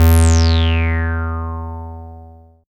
78.05 BASS.wav